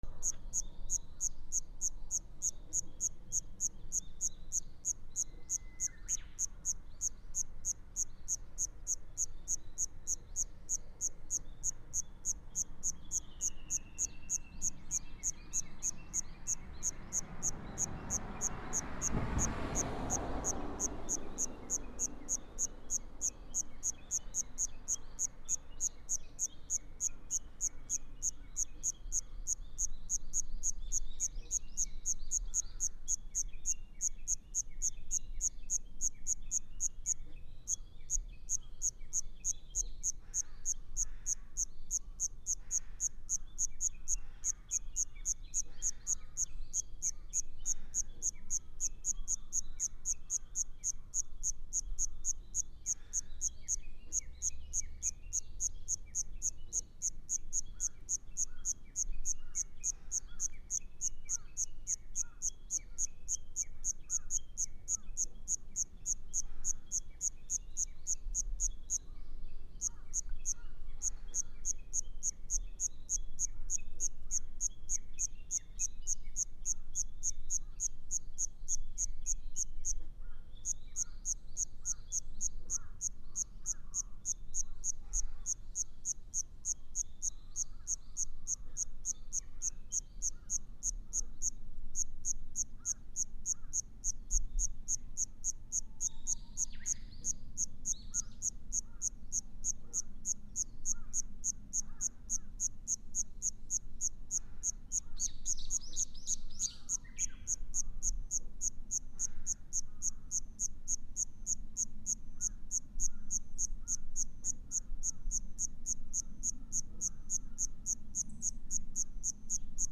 / B｜環境音(自然) / B-30 ｜虫の鳴き声 / 虫10_虫の鳴き声10_街、近郊(少なめ)
虫 1匹中心 ジッジッ
草むら NT4 奈良県松尾山